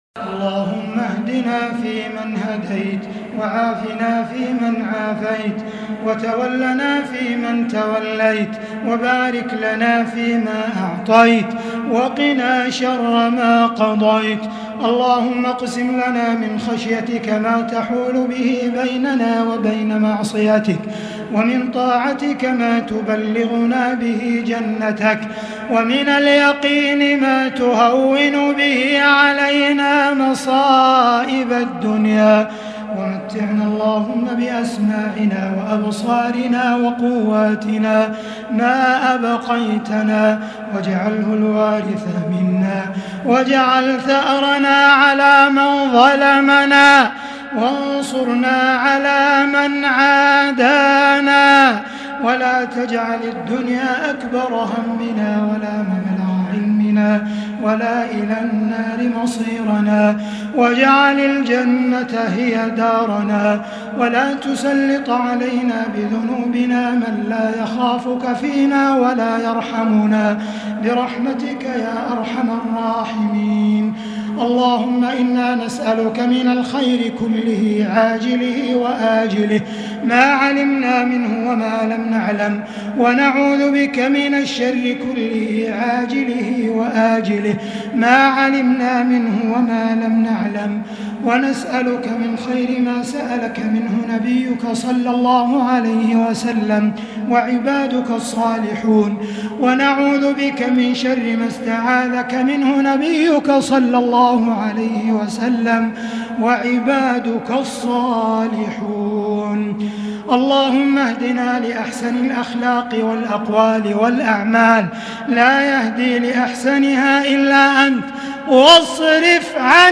دعاء ليلة 7 رمضان 1441هـ > تراويح الحرم المكي عام 1441 🕋 > التراويح - تلاوات الحرمين